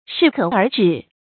注音：ㄕㄧˋ ㄎㄜˇ ㄦˊ ㄓㄧˇ